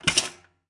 toaster
描述：sound of a bread toaster. RodeNT4>Felmicbooster>iRiverH120(Rockbox)/sonido de una tostadora de pan
标签： household kitchen toaster
声道立体声